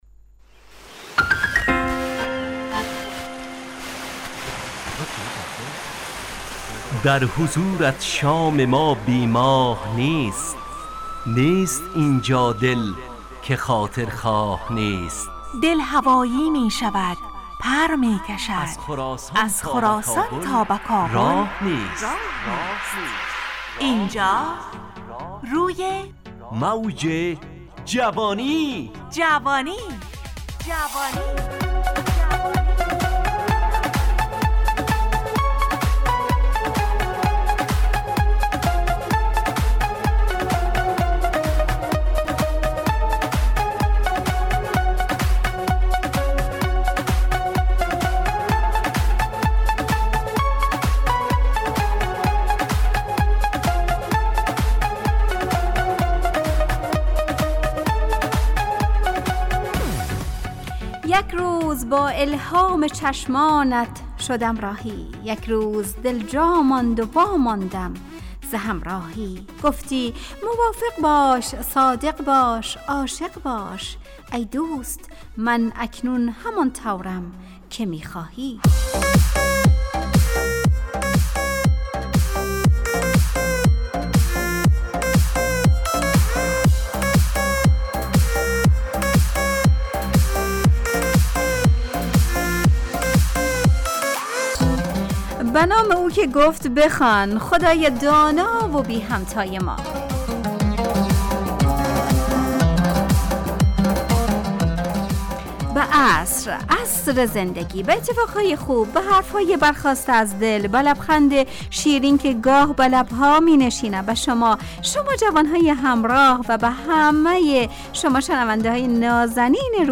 همراه با ترانه و موسیقی مدت برنامه 55 دقیقه . بحث محوری این هفته (اگر کتاب نخوانیم...)